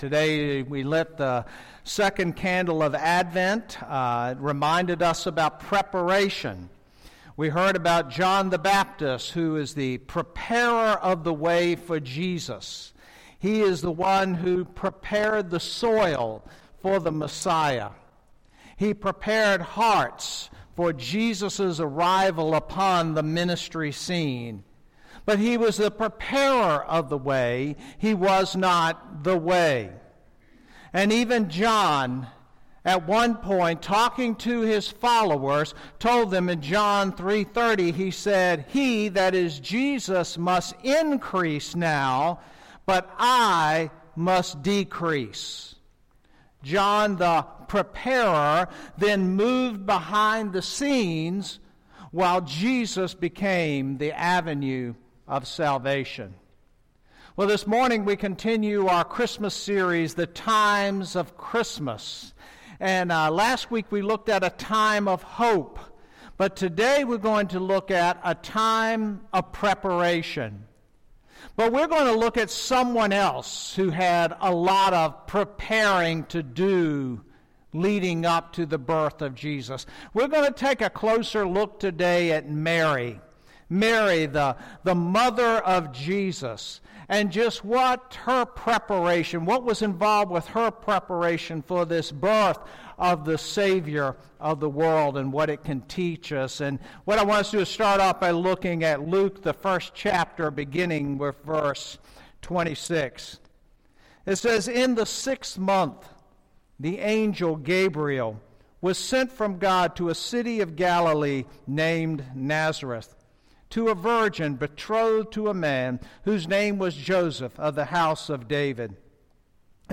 Sermons | Calvary Baptist Bel Air